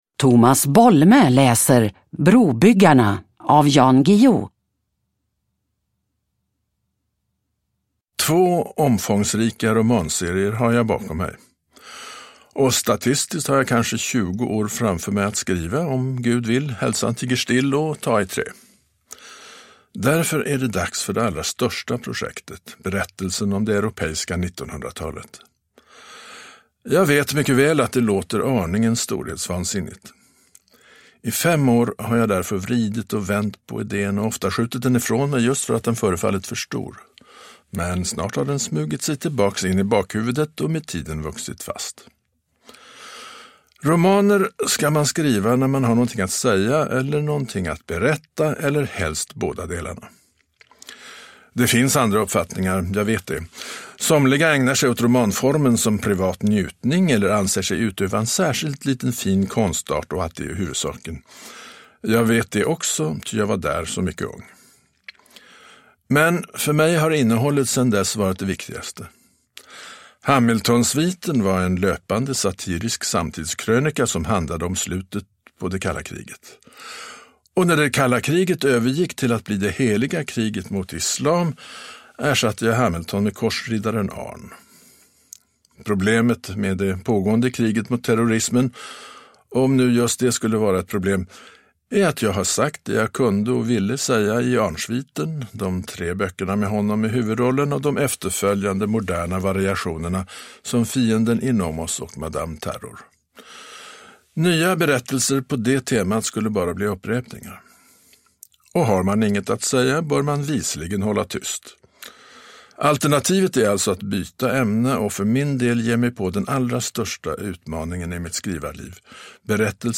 Brobyggarna / Ljudbok